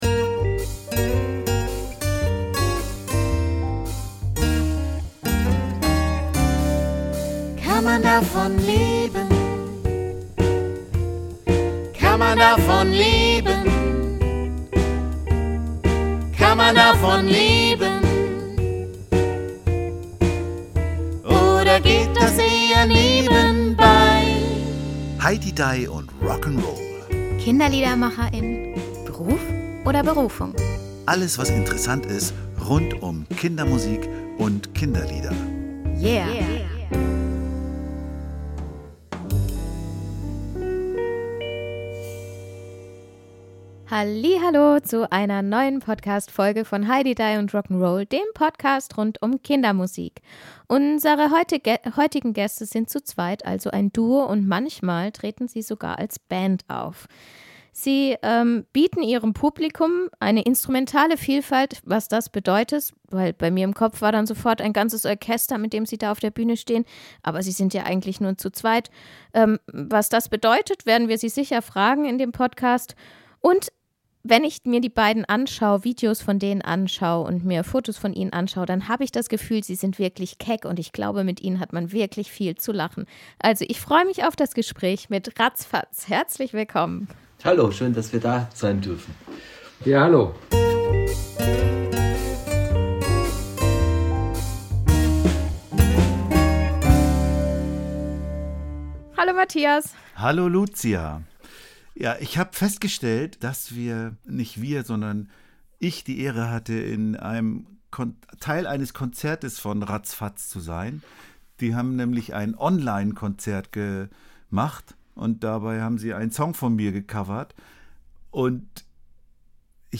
Sie kommen aus Tirol in Österreich - doch wenn man genau nachfragt, stimmt das nur teilweise. Sie feiern mit Streicherzoo und Weihnachts-CD und ganz besonderen Puppen. All das wird genau geklärt im Gespräch